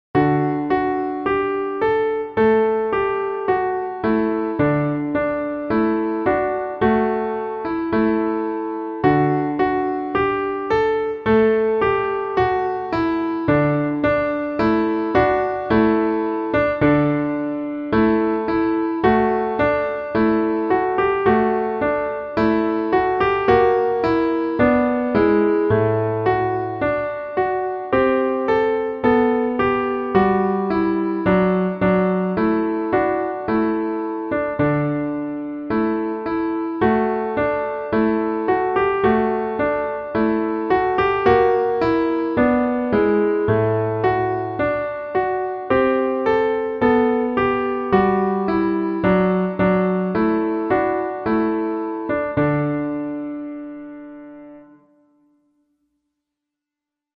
Ode To Joy Melody Piano
Composer: Ludwig van Beethoven
Ode-to-joy-melody-piano.mp3